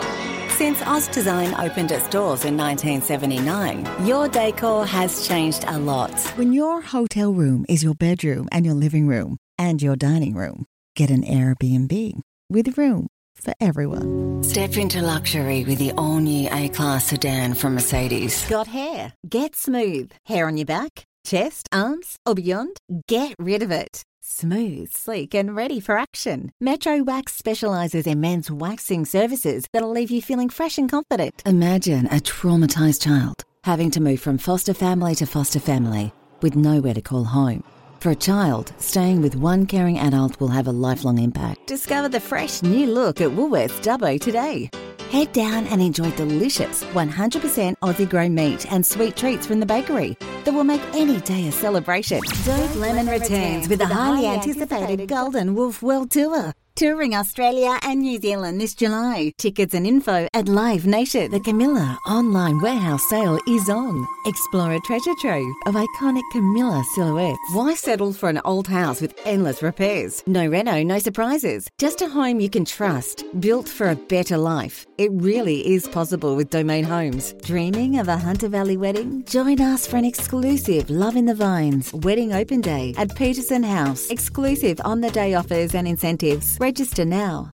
Australian female voiceover artist, with a voice often described as:
- Warm, Natural, Elegant, Conversational, Upbeat, Playful, Vibrant, Seductive, Sassy, Bold
- Authoritative, down to earth.
- Rode NT1 Microphone
Radio Commercials
Mixed Spotify Commercials 2025